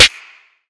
Snare (6).wav